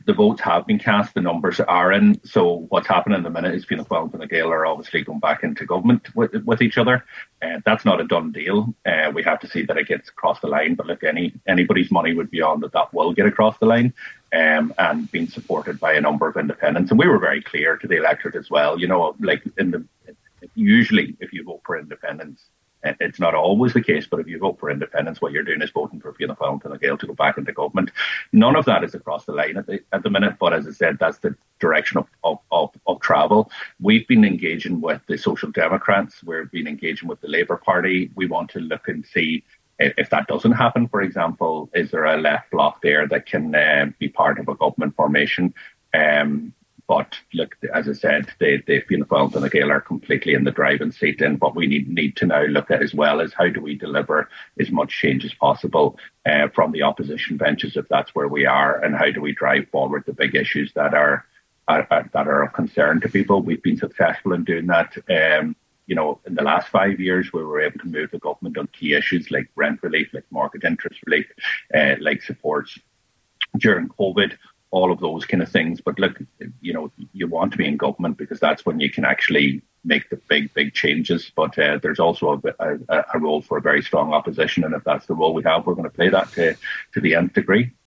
However, on today’s Nine til Noon Show, Pearse Doherty, the party’s Finance Spokesperson and Deputy Leader, said the reality is that with Fianna Fail and Fine Gael likely to secure the support of independents, the chance of a government of the left is very remote……….